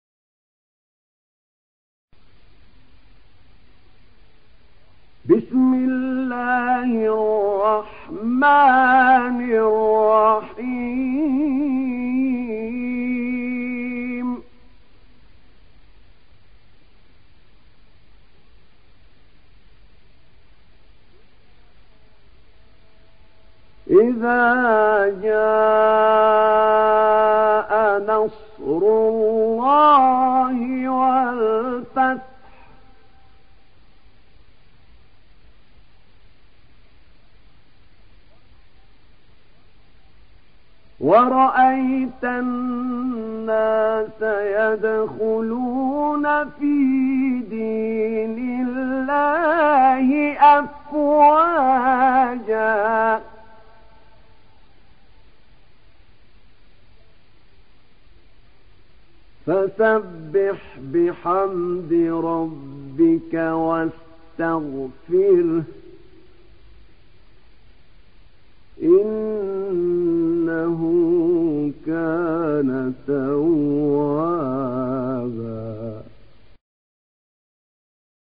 تحميل سورة النصر mp3 بصوت أحمد نعينع برواية حفص عن عاصم, تحميل استماع القرآن الكريم على الجوال mp3 كاملا بروابط مباشرة وسريعة